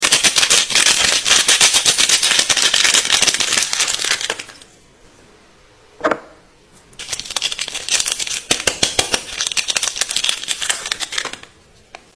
Relaxing Soap Haul ASMR | Sound Effects Free Download